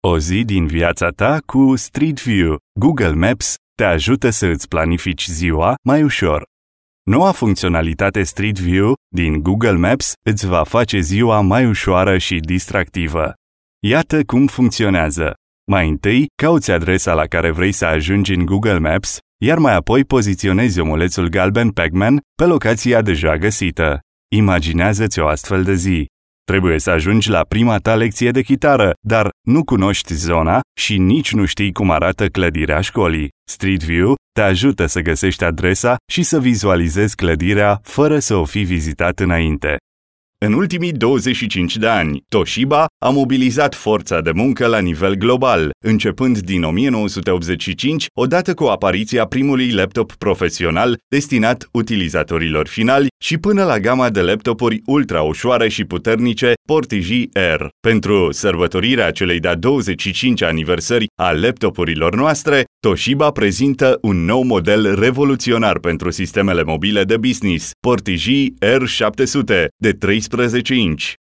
Romanian native, male voiceover with more than 10 years of experience.
Sprechprobe: eLearning (Muttersprache):